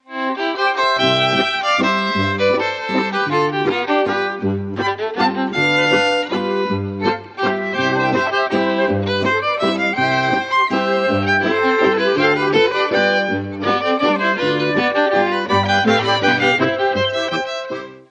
Musik aus dem Mostviertel